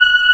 Index of /90_sSampleCDs/AKAI S6000 CD-ROM - Volume 1/VOCAL_ORGAN/BIG_CHOIR